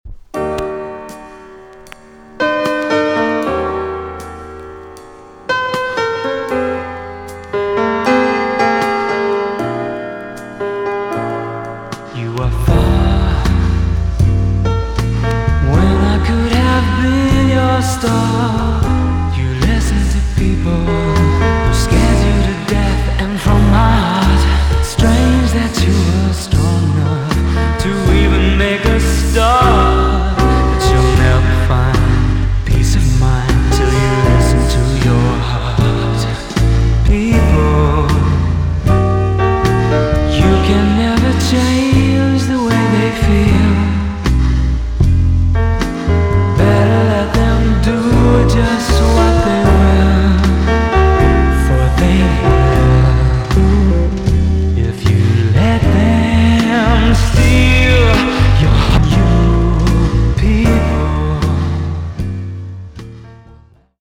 EX-~VG+ 少し若干軽いチリノイズがありますが良好です。
1987 , NICE BALLAD TUNE!!